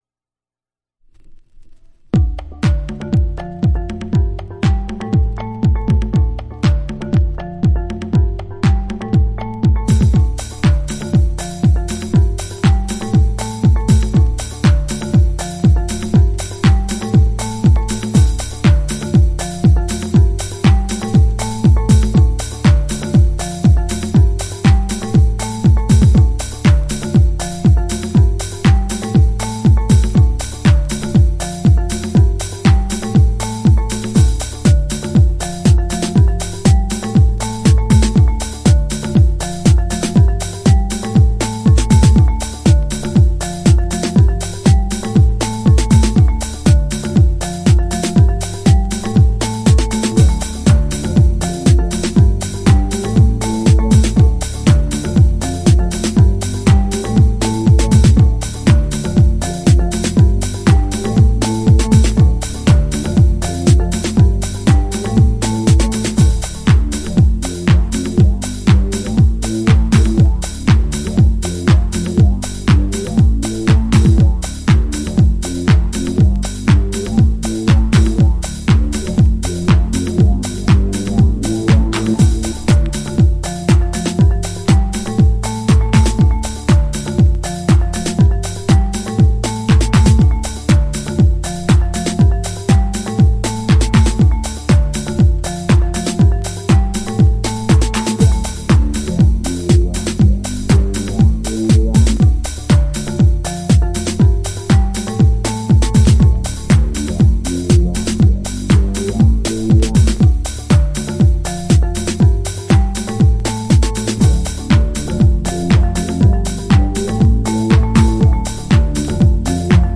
TOP > Early House / 90's Techno > VARIOUS